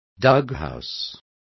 Complete with pronunciation of the translation of doghouses.